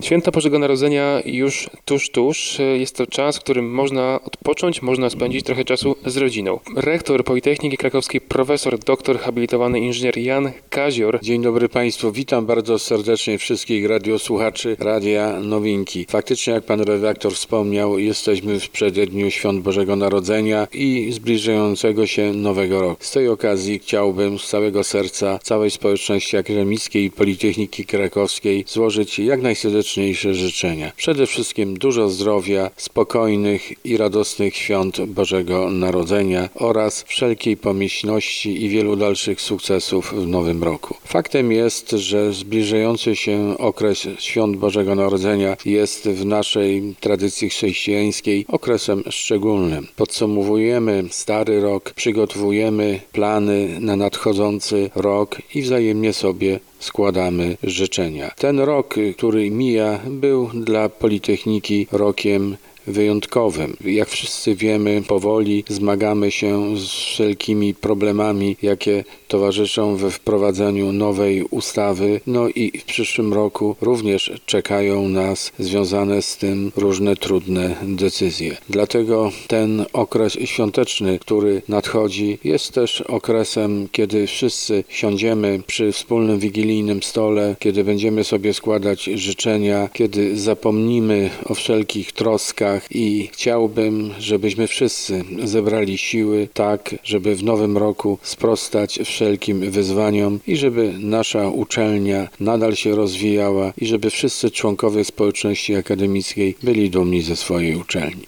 Życzenia Rektora PK